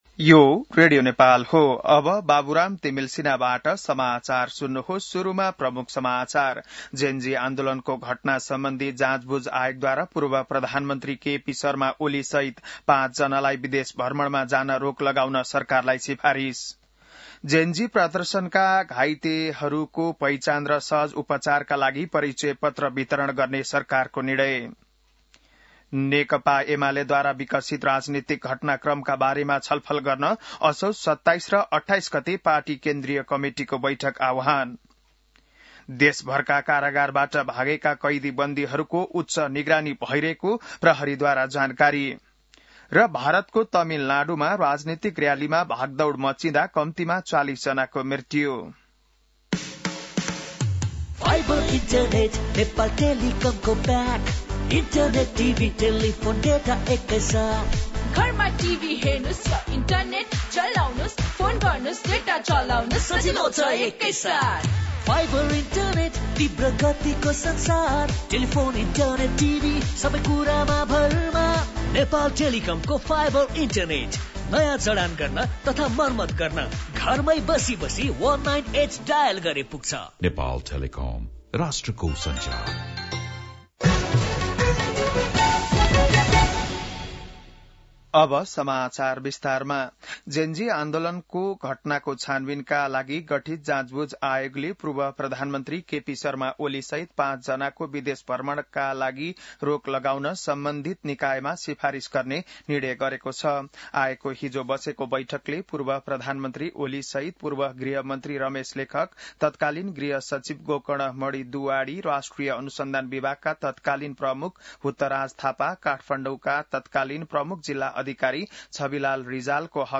बिहान ७ बजेको नेपाली समाचार : १३ असोज , २०८२